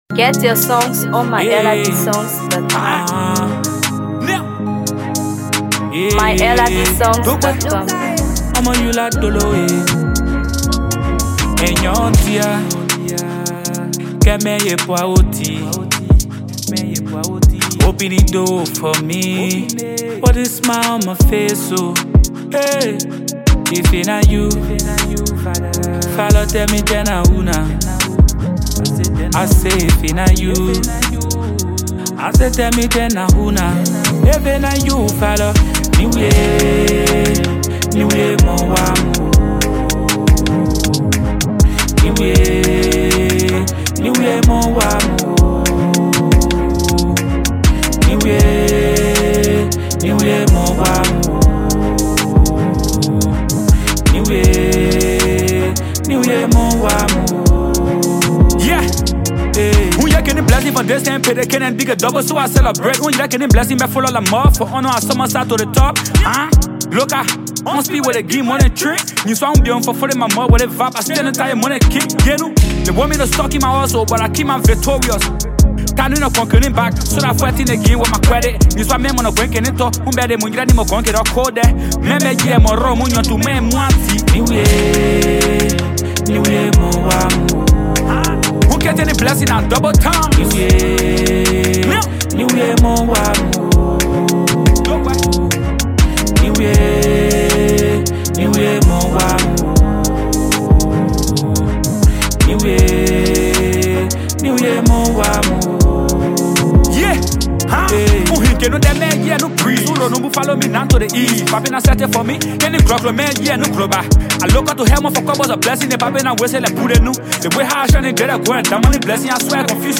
Afro Pop
Known for his smooth vocals and heartfelt storytelling